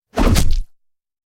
Звуки удара ножом
Метательный нож вонзается в тело мужчины